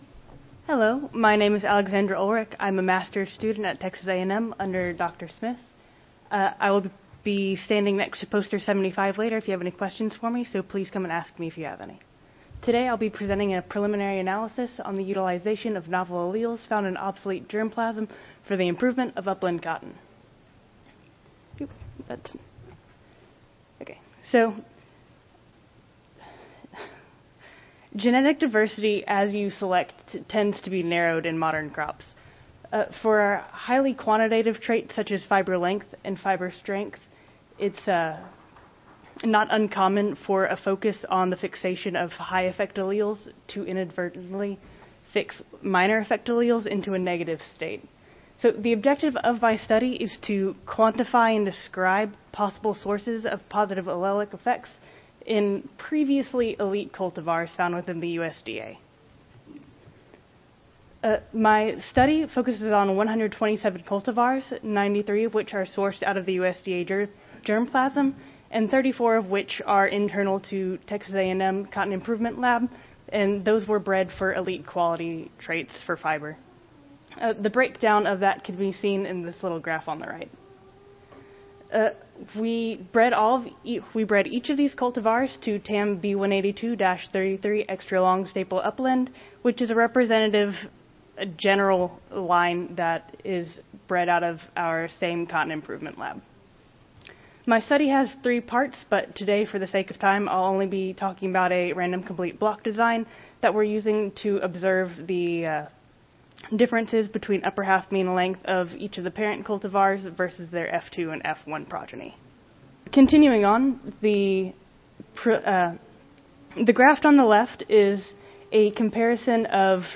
Cotton Improvement - Lightning Talks Student Competition
Audio File Recorded Presentation